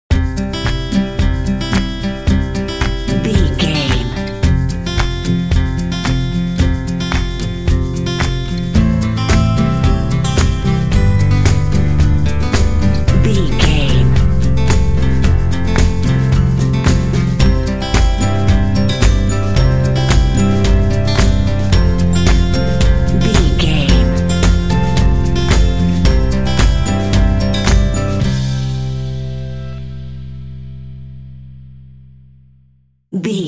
Uplifting
Ionian/Major
joyful
acoustic guitar
bass guitar
drums
electric piano
indie
pop
contemporary underscore